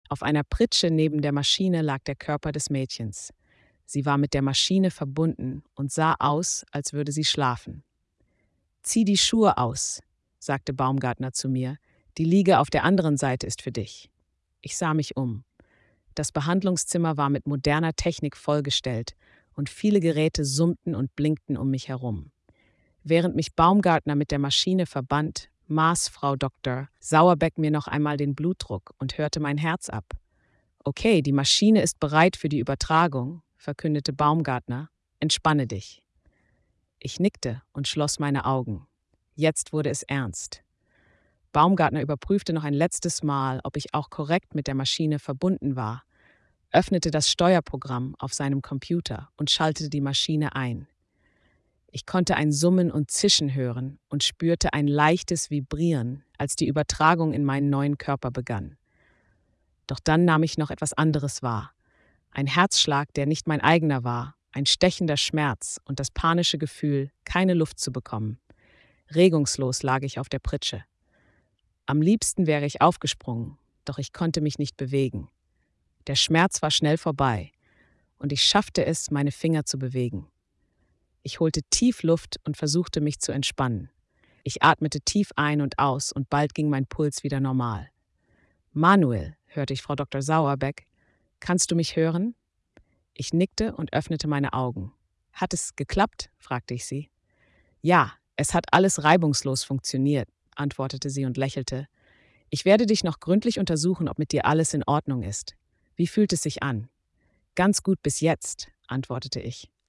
Text to Speech
Diesen Text habe ich nun von verschiedenen KI-Werkzeugen lesen lassen.